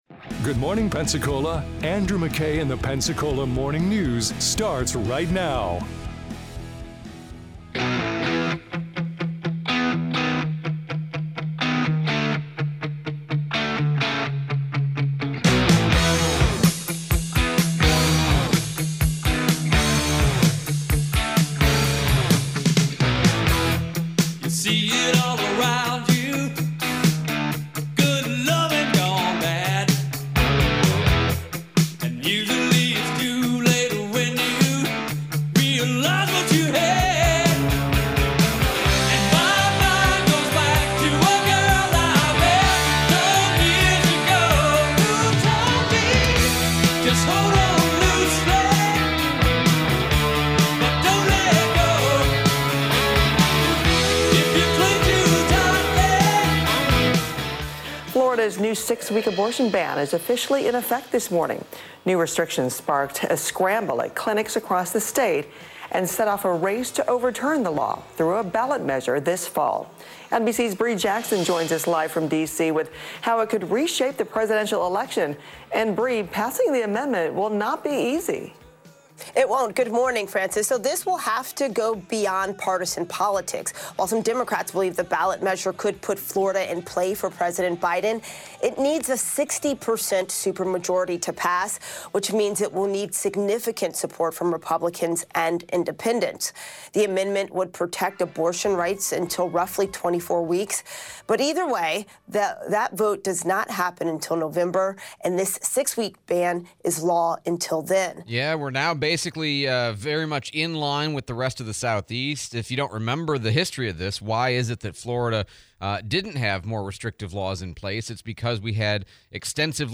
The 6-week abortion ban in Florida / Replay of interview with Escambia County Sheriff Chip Simmons